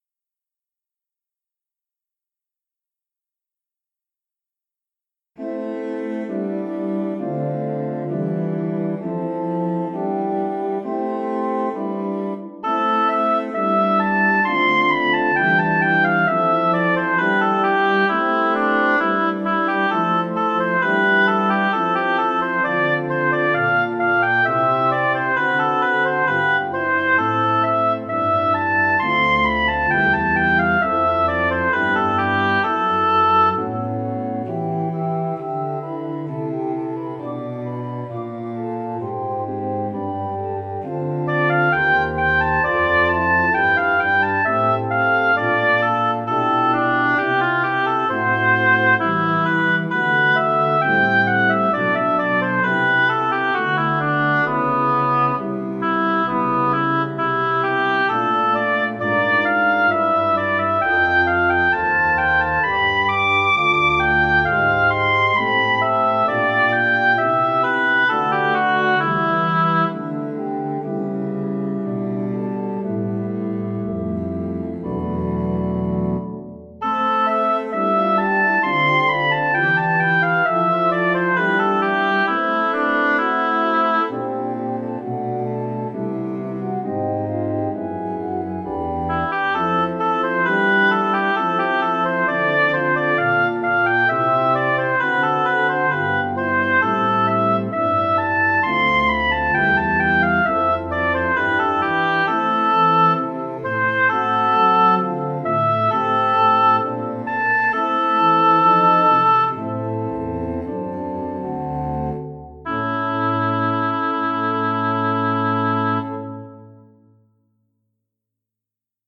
Ritornella – Ritournelle pour Flûte de Pan et harmonium, ou 1 instrument mélodique (Ut ou Sib) et clavier.